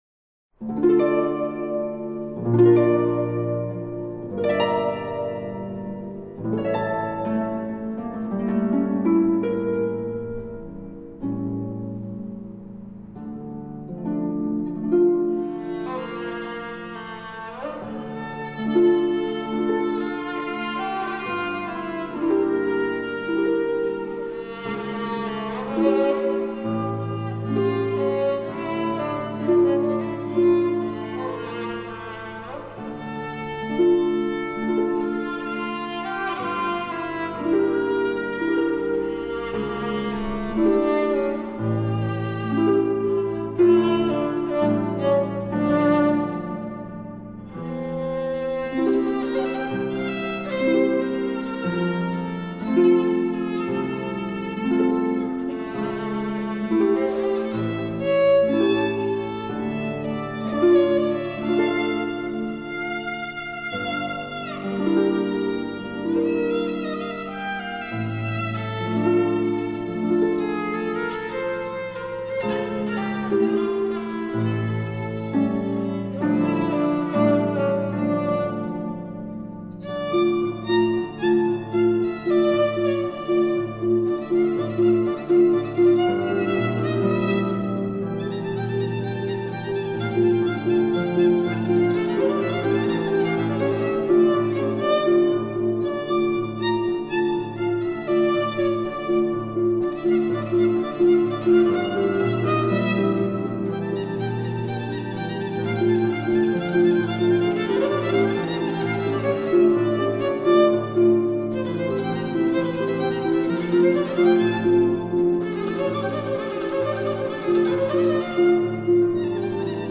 Ter herinnerring aan het 60 jarig jubileumconcert van leerlingen van het Driestarcollege in de grote kerk te Gouda